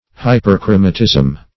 Search Result for " hyperchromatism" : The Collaborative International Dictionary of English v.0.48: Hyperchromatism \Hy`per*chro"ma*tism\, n. The condition of having an unusual intensity of color.
hyperchromatism.mp3